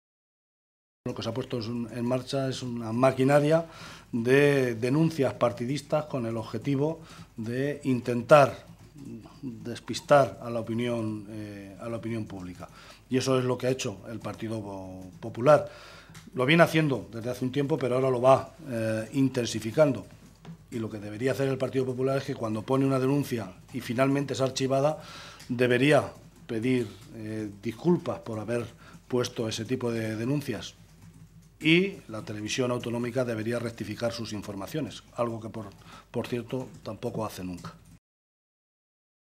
Martínez Guijarro realizaba estas declaraciones en la rueda de prensa posterior a la reunión que han mantenido miembros del Grupo socialista con representantes sindicales de este sector de la región.